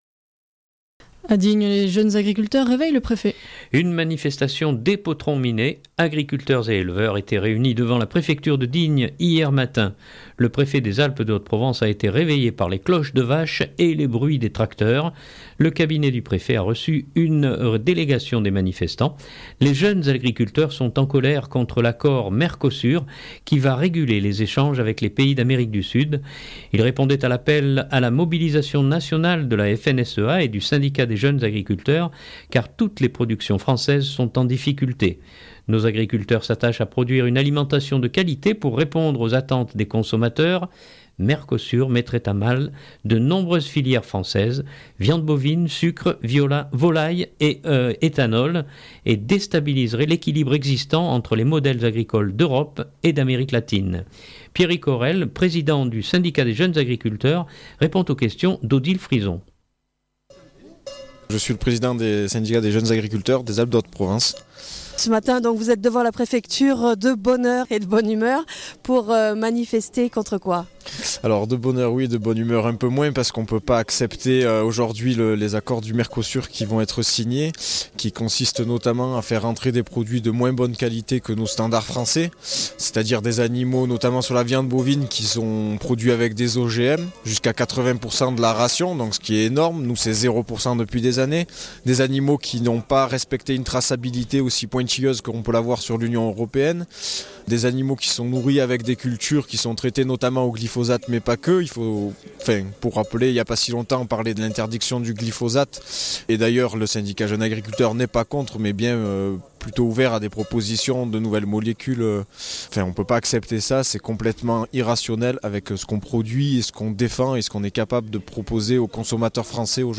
Une manifestation dès potron-minet : agriculteurs et éleveurs étaient réunis devant la préfecture de Digne hier matin. Le préfet des Alpes de Haute-Provence a été réveillé par les cloches de vaches et le bruit des tracteurs.